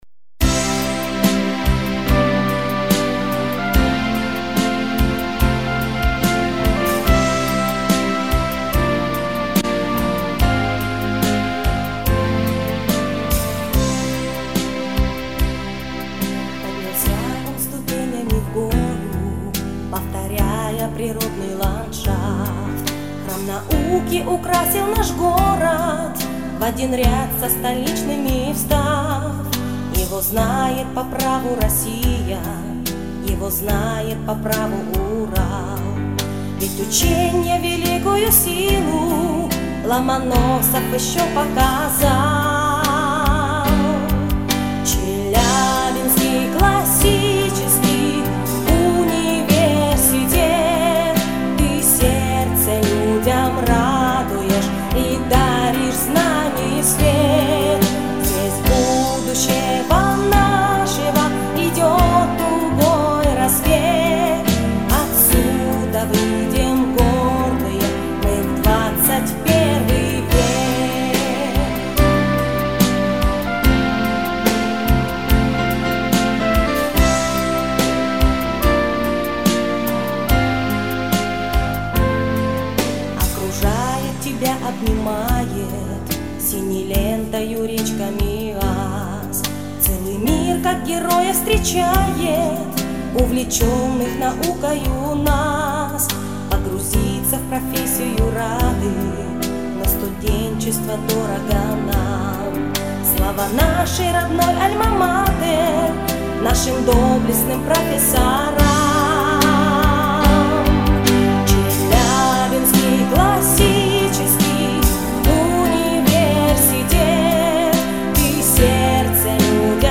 Гимн